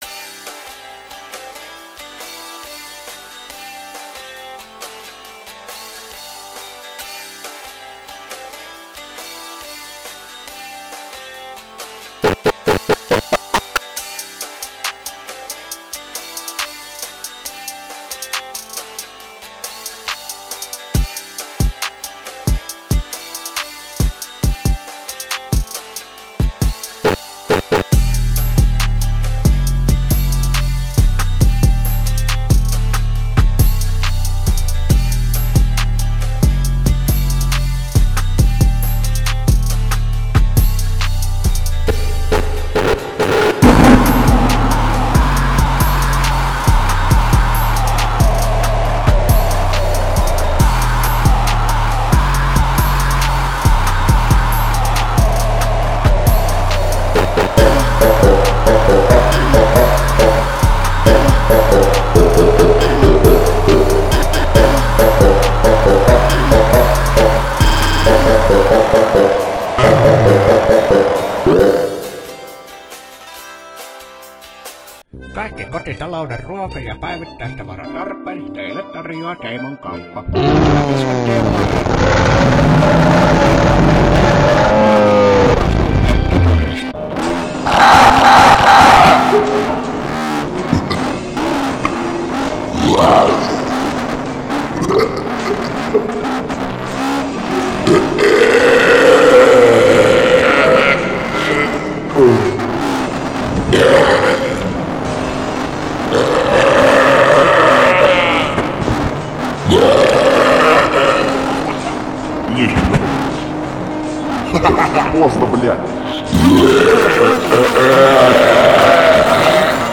Personally, I'm far from being involved in the craft of music production, but two of my friends make some pretty good beats.